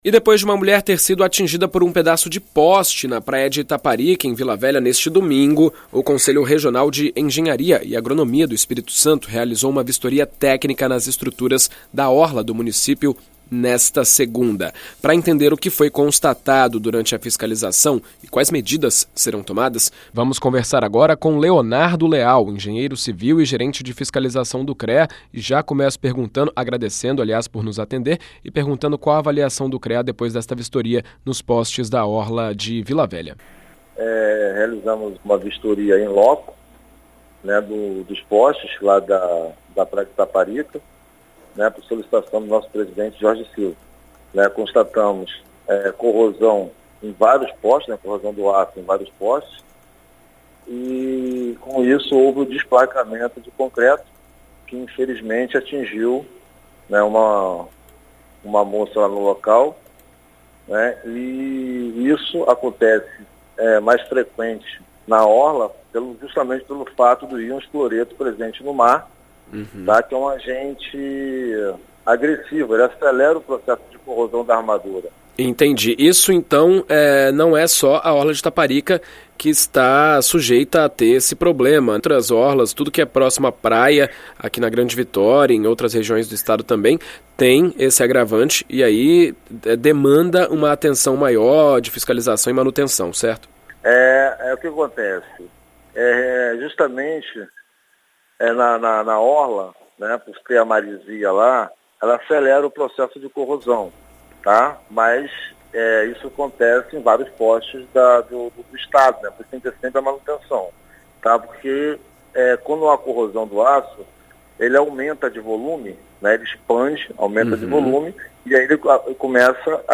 Em entrevista à rádio BandNews FM ES